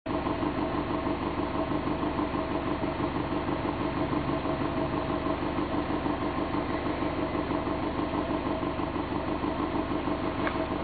Normal MUFFLER アイドル時とRacing Titan アイドル時です。
MUFFLER SUBARU Normal MUFFLER GANADOR Racing Titan MUFFLER